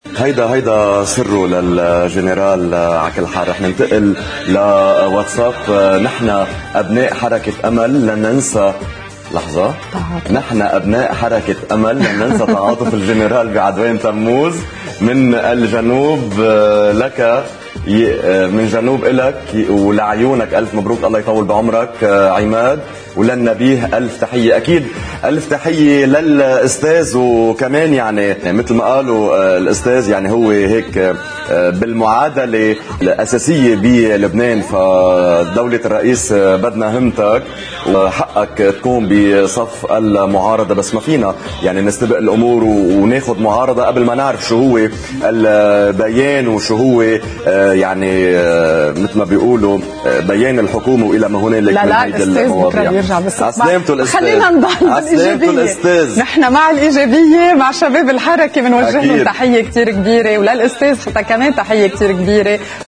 حوار اليوم الذي يتلقى اتصالات المواطنين للتعبير عن مشاعرهم بخصوص الانتخابات الرئاسية، وانتخاب العماد ميشال عون رئيساً توافقياً